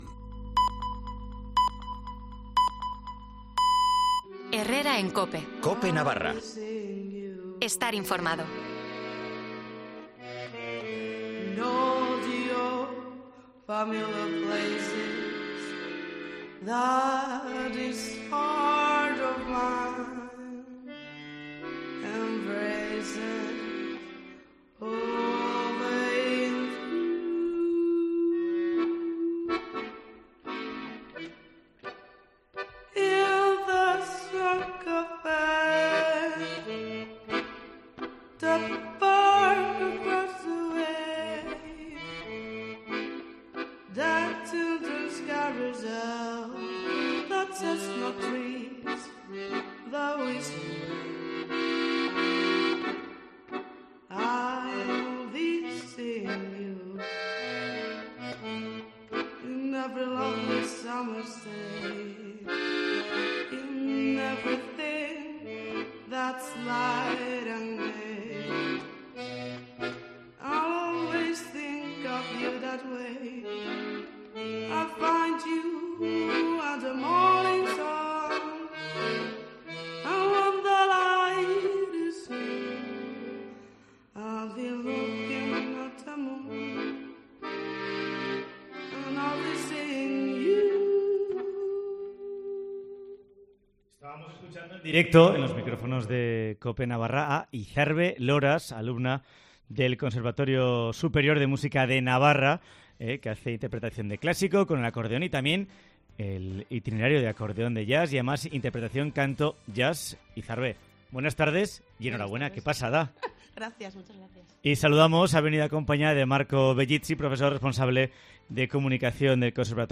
canta y toca en COPE Navarra